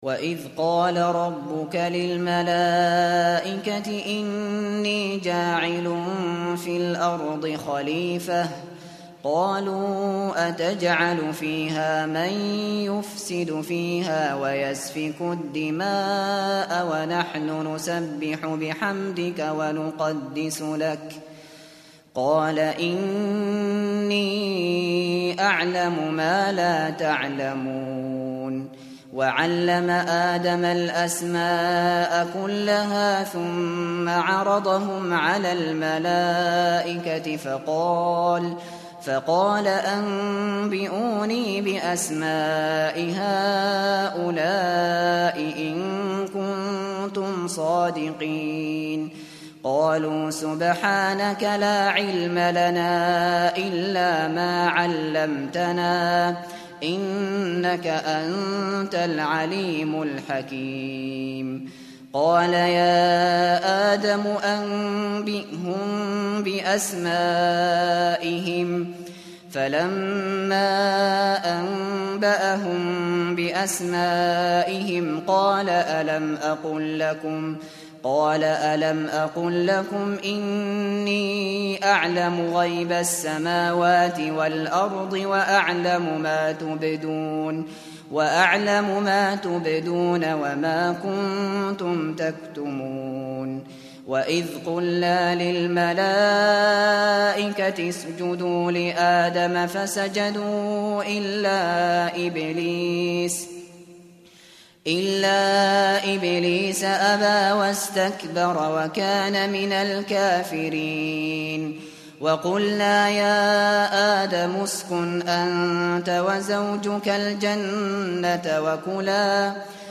Kur'ân dinlemeye başlamak için bir Hafız seçiniz.